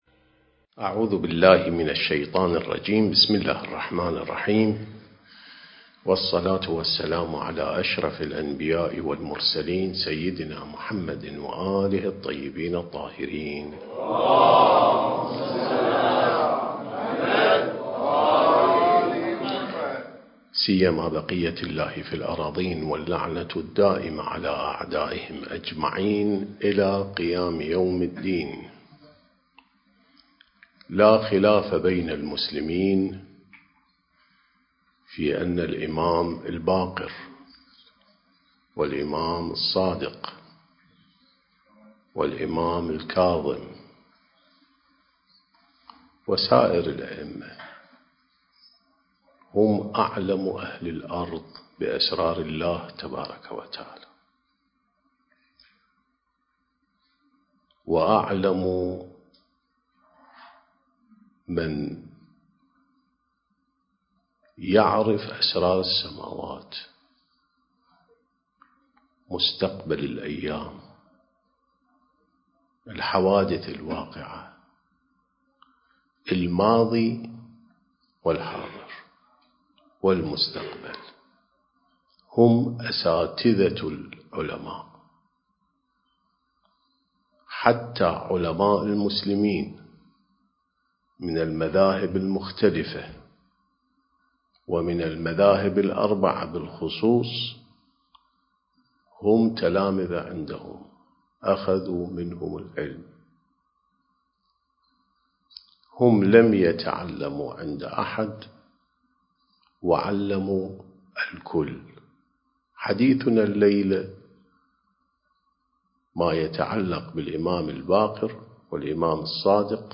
سلسلة محاضرات: الإعداد الربّاني للغيبة والظهور (9)